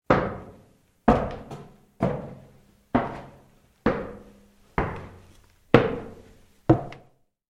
Звуки шагов по лестнице
Громкое погружение в подвал